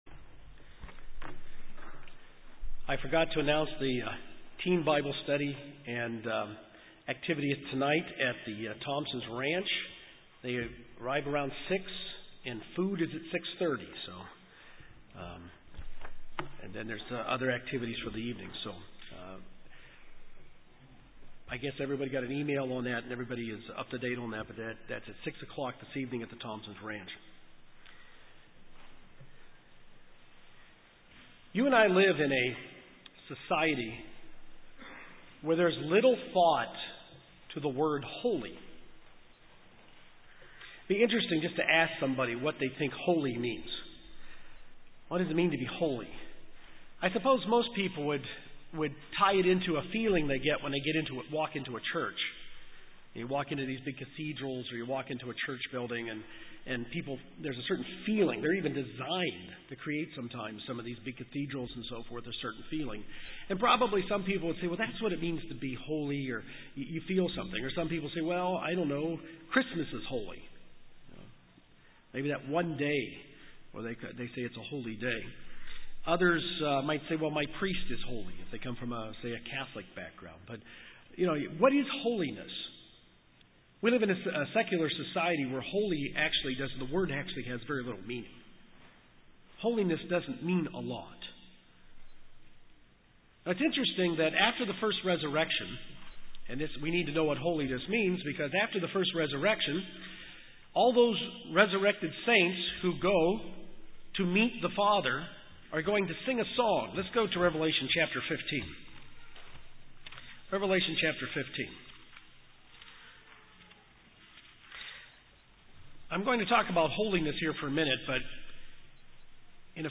This sermon was given in the middle of the Ministry of Reconciliation Series and fits into, but is not part of the series.